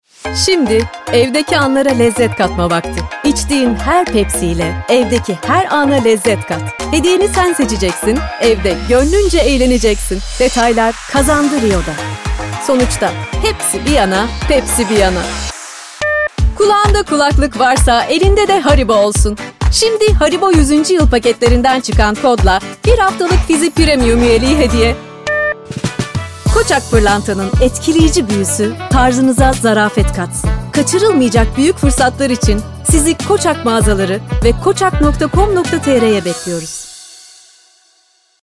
Ses kayıtlarını stüdyomuzda seslendirme yönetmeni, ses teknisyeni ve son teknoloji kayıt donanımları ile kaydediyoruz.